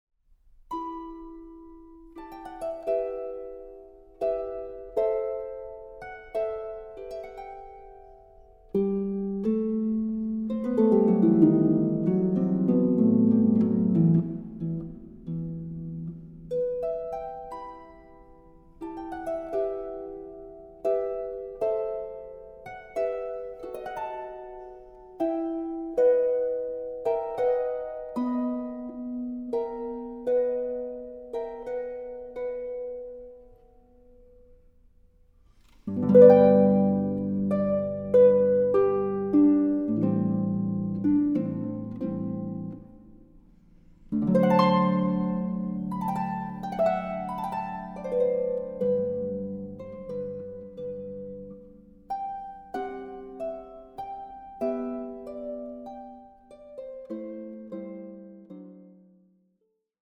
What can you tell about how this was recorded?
Recording: Festeburgkirche Frankfurt, 2024